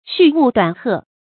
續鶩短鶴 注音： ㄒㄩˋ ㄨˋ ㄉㄨㄢˇ ㄏㄜˋ 讀音讀法： 意思解釋： 比喻違失事物本性，欲益反損。